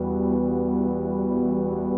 arcaneloop.wav